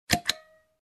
На этой странице собраны различные звуки щелчков пальцами – от четких и звонких до приглушенных и мягких.
Светильник